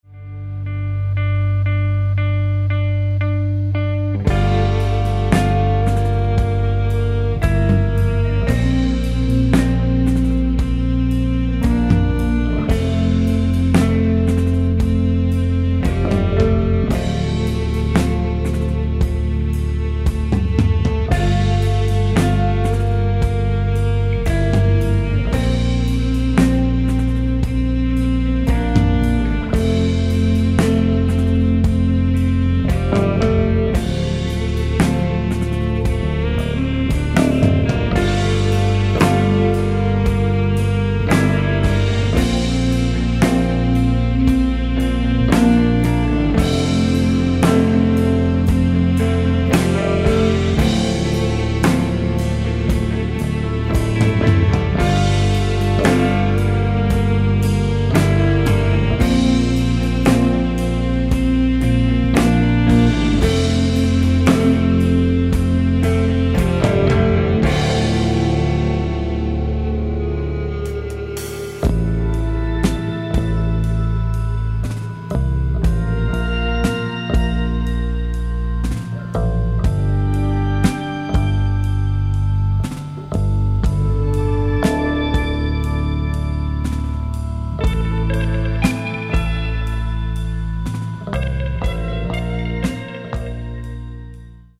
Каталог -> Электроакустические опыты -> Пост-рок